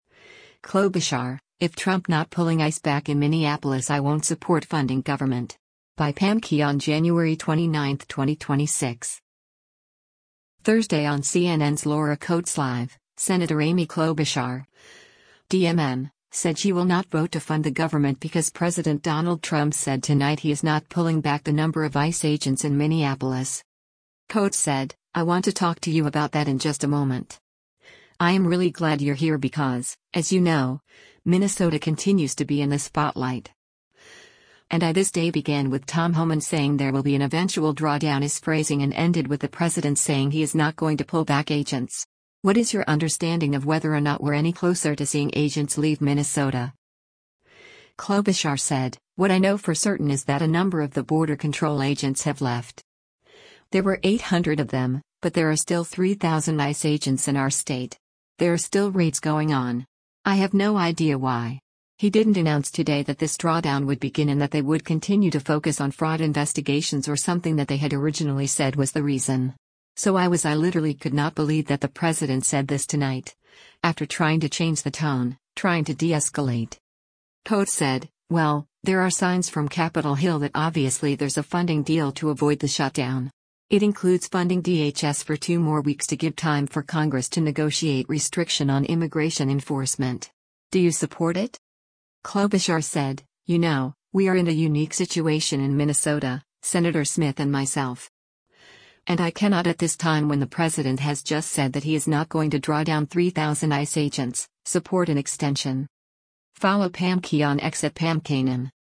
Thursday on CNN’s “Laura Coates Live,” Sen. Amy Klobuchar (D-MN) said she will not vote to fund the government because President Donald Trump said tonight he is not pulling back the number of ICE agents in Minneapolis.